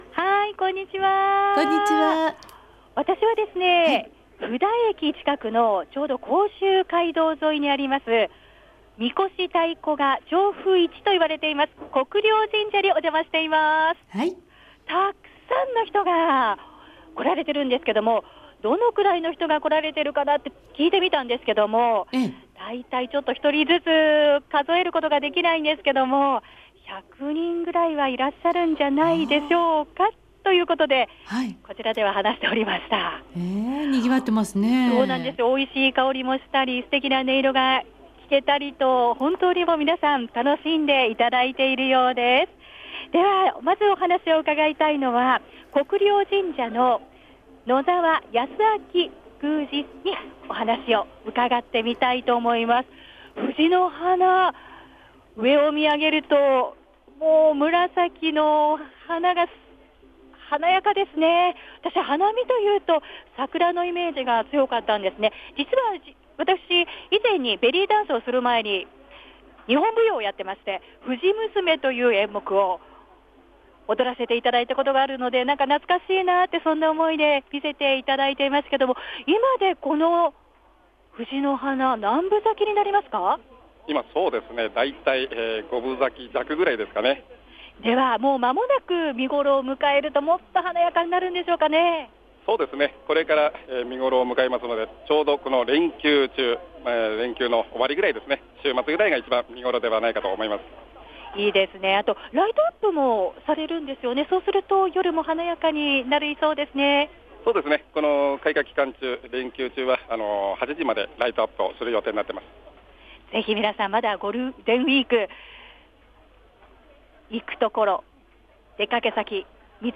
国領神社 藤乃花まつり